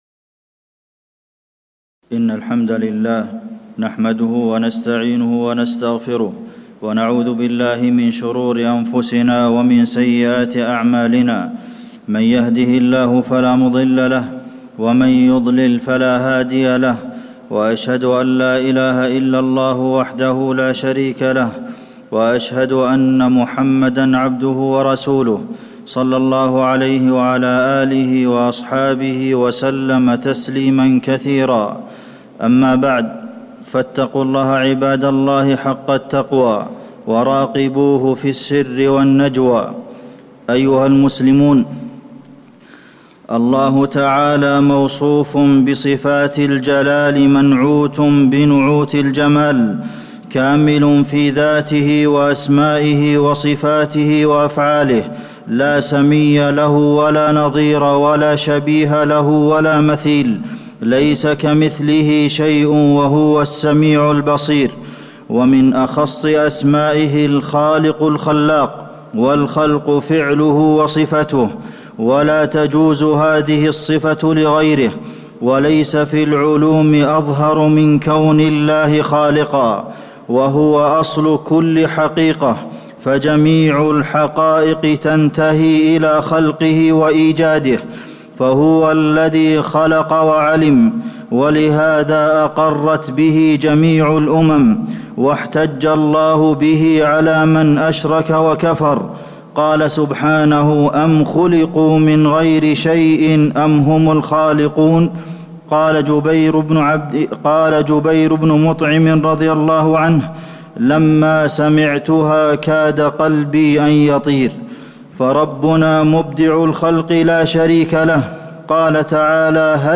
تاريخ النشر ٥ ذو القعدة ١٤٣٨ هـ المكان: المسجد النبوي الشيخ: فضيلة الشيخ د. عبدالمحسن بن محمد القاسم فضيلة الشيخ د. عبدالمحسن بن محمد القاسم عرش الرحمن